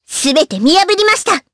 Hanus-Vox_Skill4_jp.wav